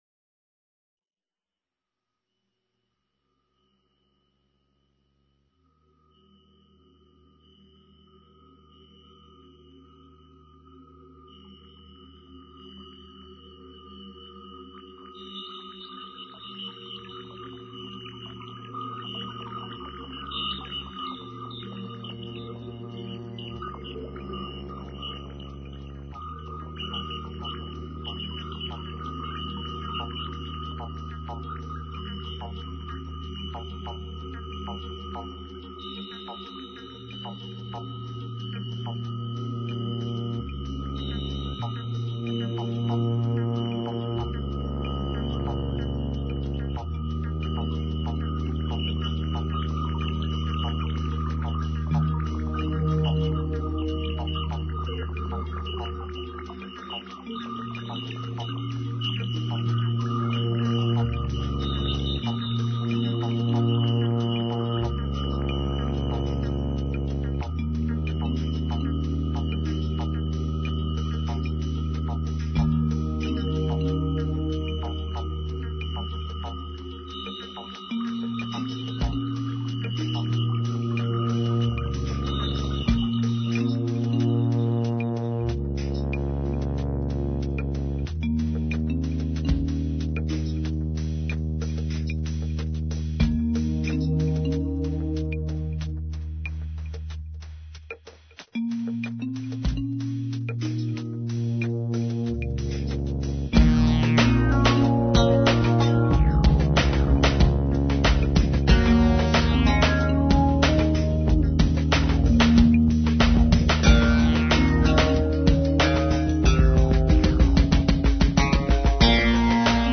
dance/electronic